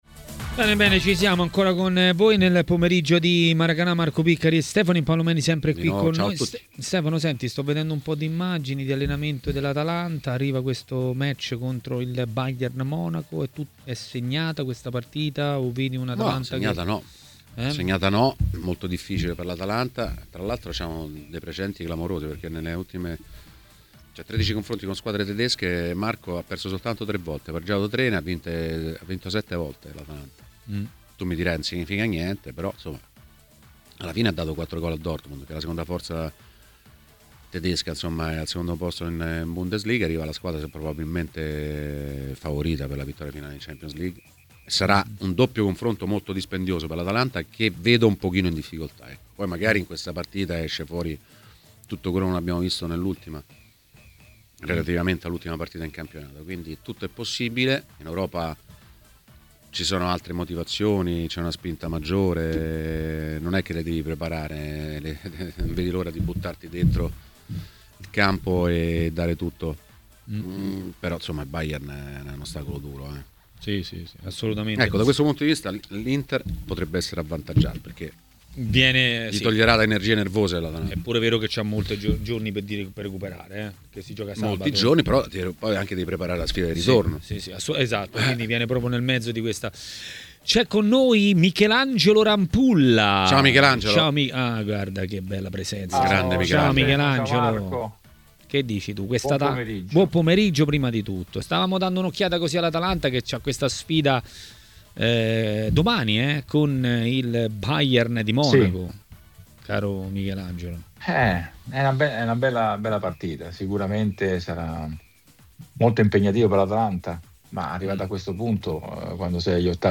Ospite di Maracanà, nel pomeriggio di TMW Radio, è stato l'ex portiere Michelangelo Rampulla.